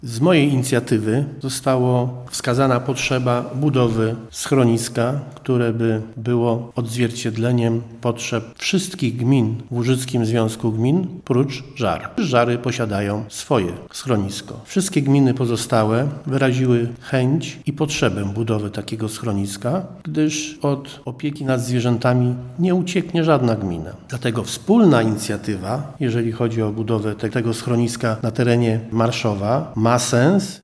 Jak mówi gospodarz Żagania, Andrzej Katarzyniec, schronisko znajdowałoby się obok Zakładu Zagospodarowania Odpadów w Marszowie: